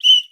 Whistle
Field Hockey Foul.wav